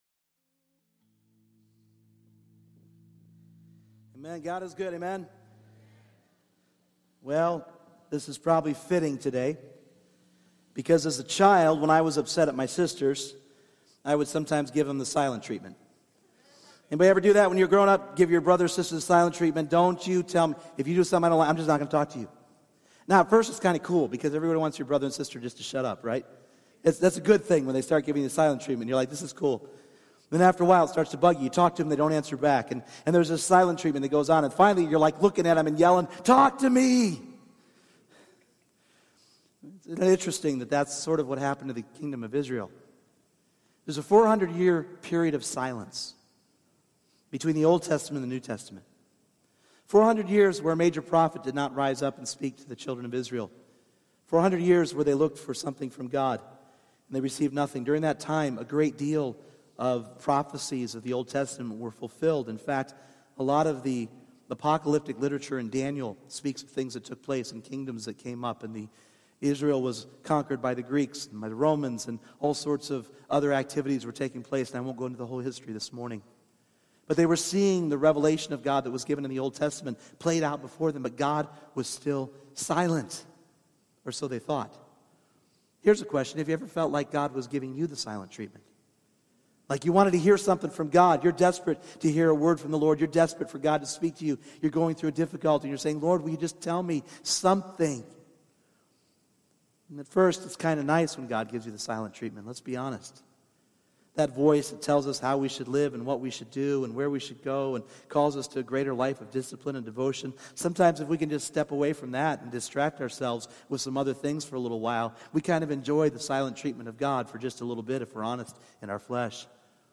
Individual Messages Service Type: Sunday Morning The world is hungry for the prophetic voice of the church to declare God's redemptive plan.